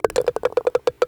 Golf_Ball_Goes_In_Start.ogg